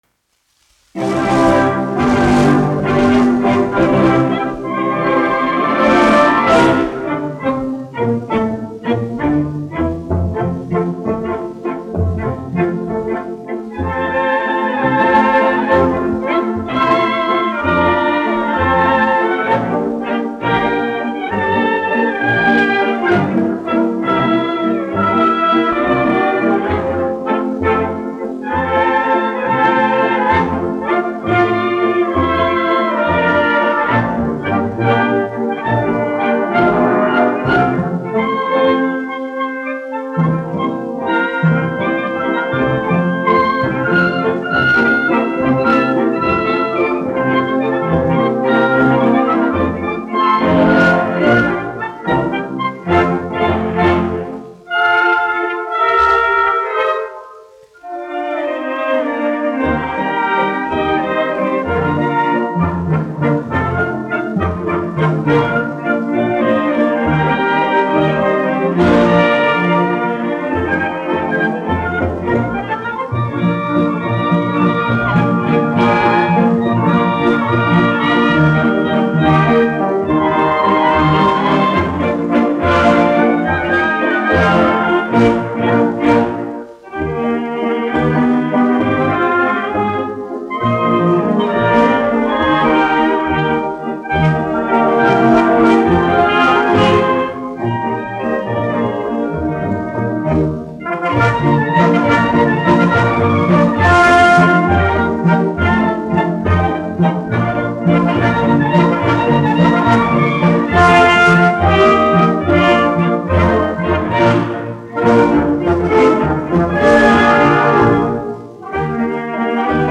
1 skpl. : analogs, 78 apgr/min, mono ; 25 cm
Valši
Orķestra mūzika
Latvijas vēsturiskie šellaka skaņuplašu ieraksti (Kolekcija)